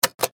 9. Нажатие кнопки игрового автомата
knopka-igrov-av.mp3